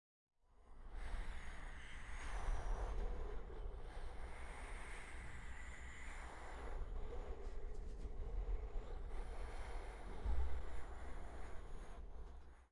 描述：Foley Final Audio1 2018